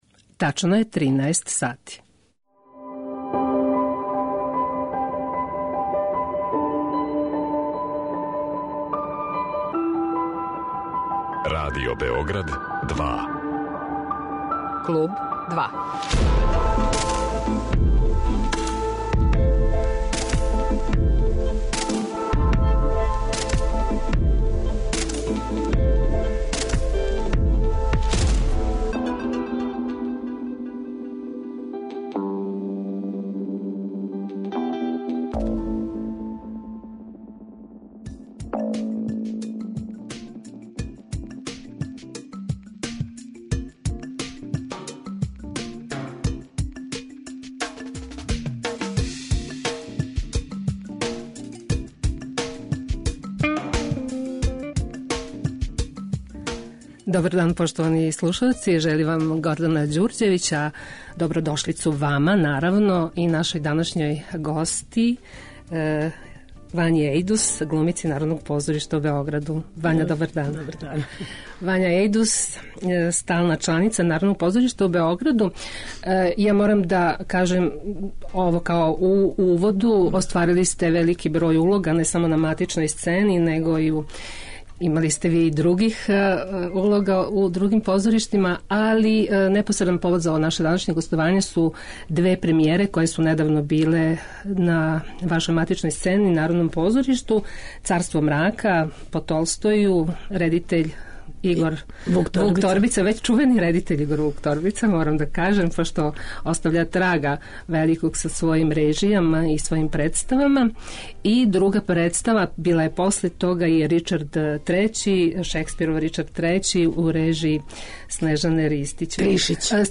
Гошћа 'Клуба 2' је драмска уметница Вања Ејдус